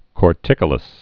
(kôr-tĭkə-ləs)